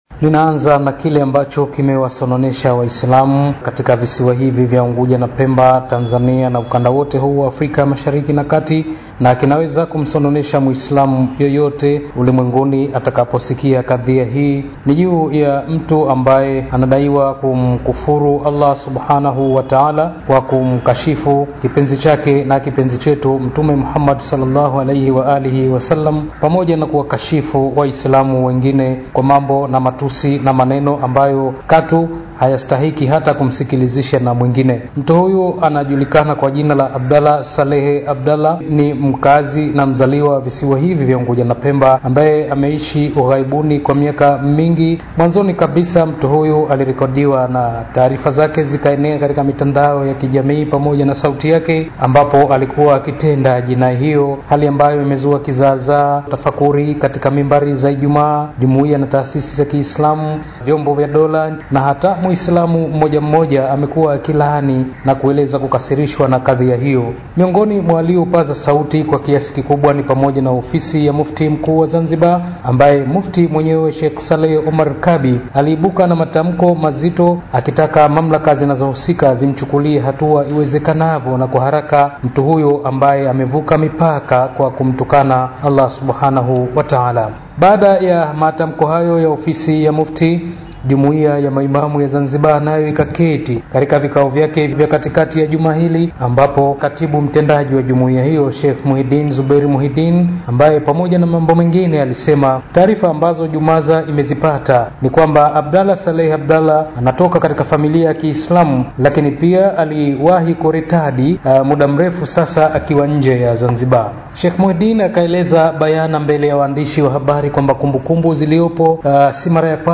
SAUTI: Aliyemtukana Mtume Muhammad SAW Zanzibar azidi kulaaniwa